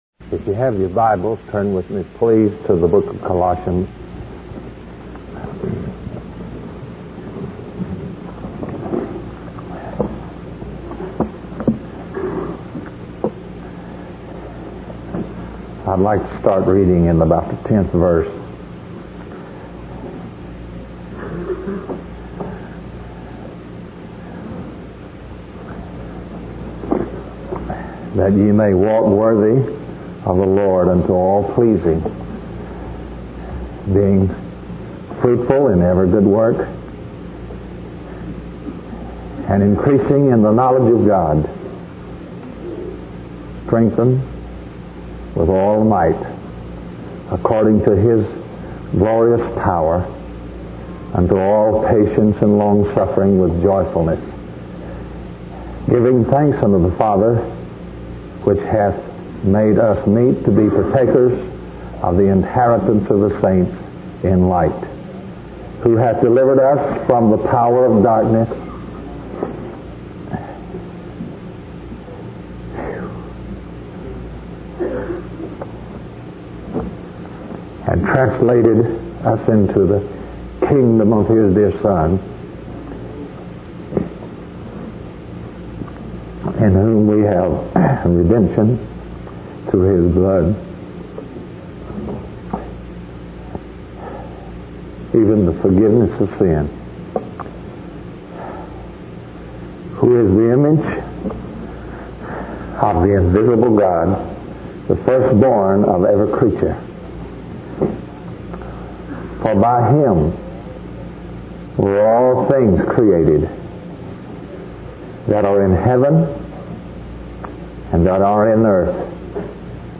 In this sermon, the preacher challenges the audience to make a commitment to God and surrender to His sovereign hand. He emphasizes the importance of allowing God to discipline and shape their lives in order to experience true revelation and appropriation of His truth. The preacher also highlights the need for a genuine encounter with the revealed Christ, rather than just a simple understanding of salvation.